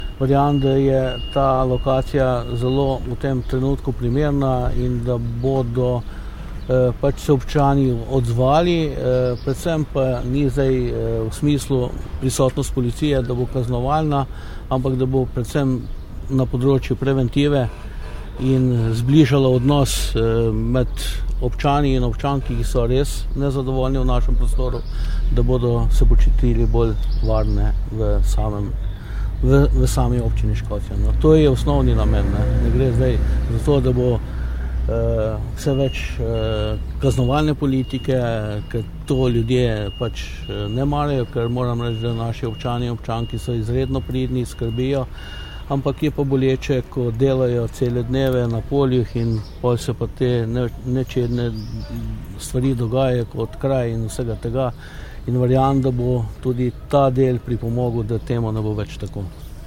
Župan Škocjana Jože Kapler verjame, da bo pisarna prispevala k izboljšanju varnostnih razmer